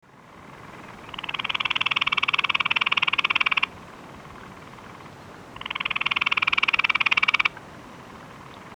Play Especie: Laliostoma labrosum Género: Laliostoma Familia: Mantellidae Órden: Anura Clase: Amphibia Título: The calls of the frogs of Madagascar.
Localidad: Madagascar: Toliara
5 Laliostoma Labrosum.mp3